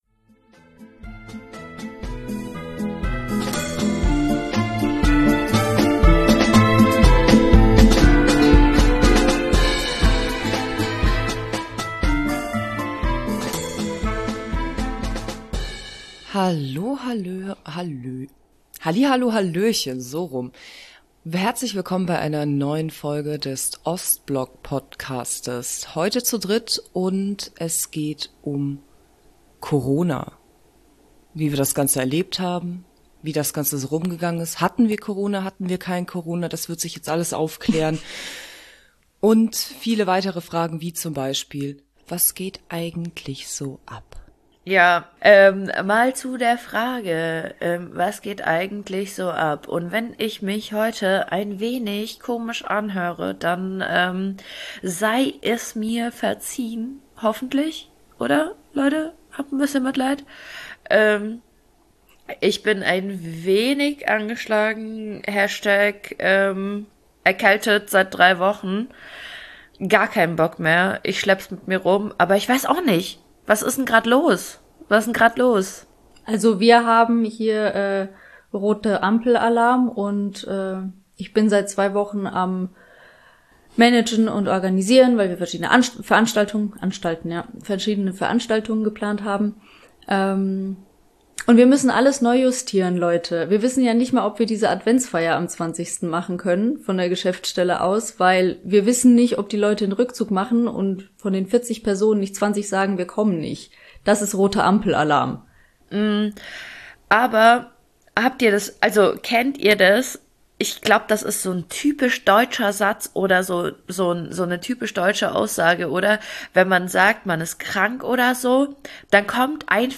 Drei Girls packen aus: Wie war es in Deutschland aufzuwachsen, wenn die Eltern ... nicht so Deutsch waren.